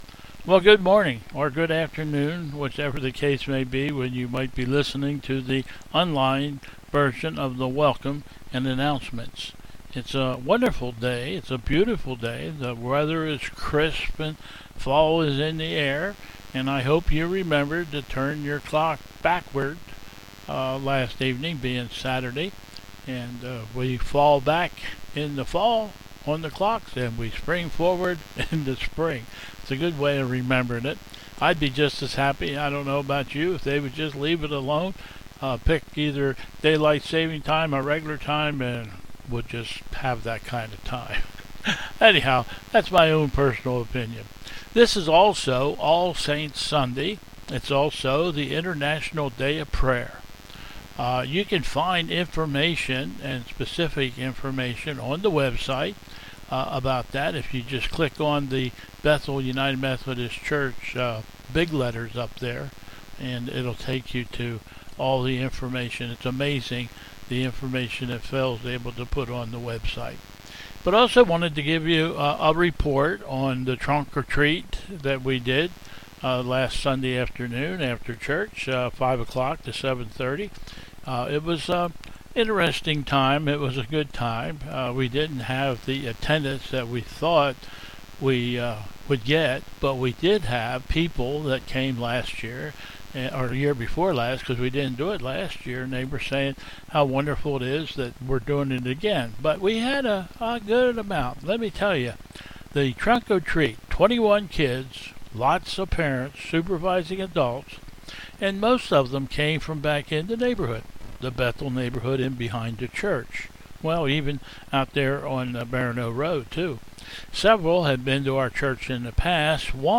Bethel 11/07/21 Service
Processional